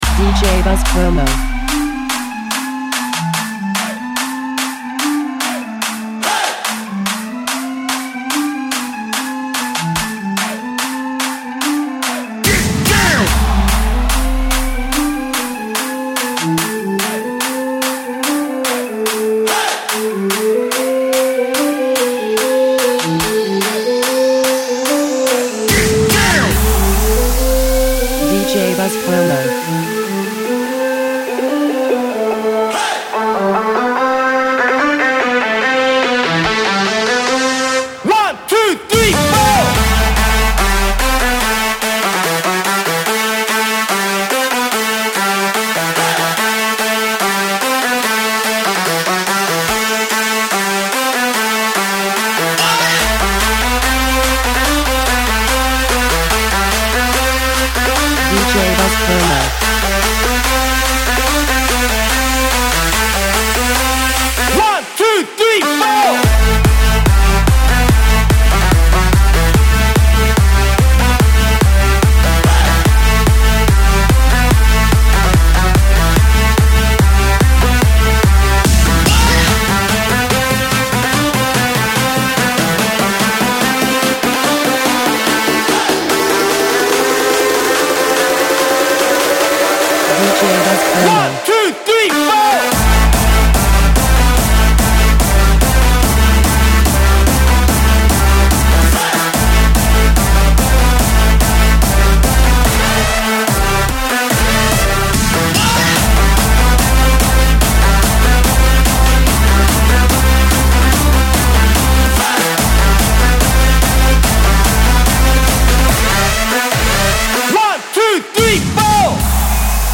The theme of famous traditional britton song